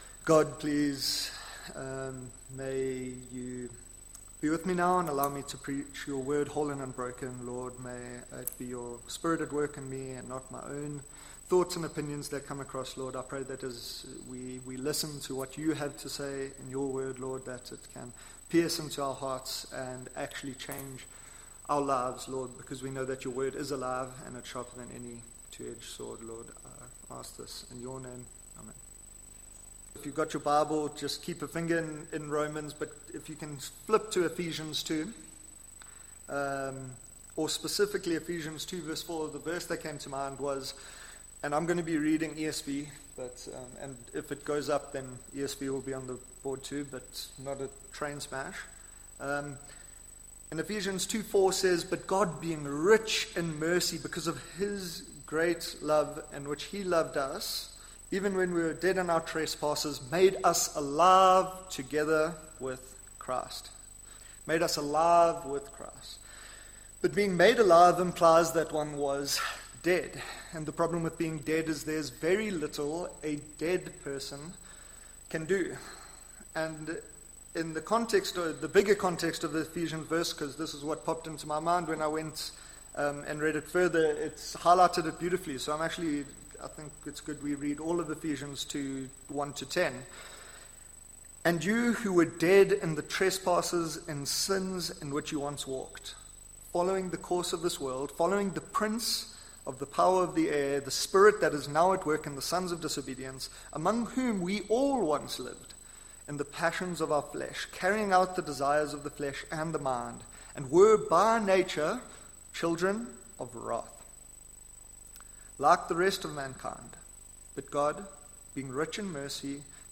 Ephesians 2:1-10 Service Type: Sunday Evening Well and truly Dead The dead who have died?